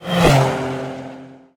car6.ogg